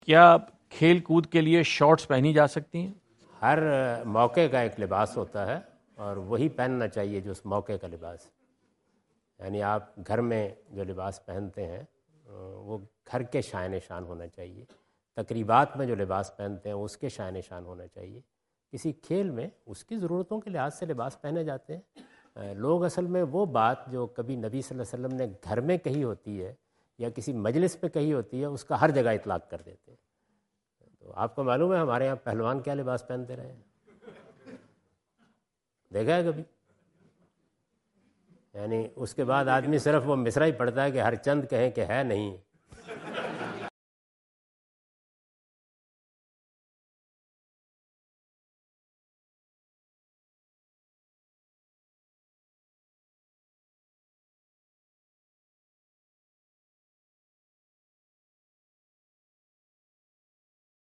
Category: English Subtitled / Questions_Answers /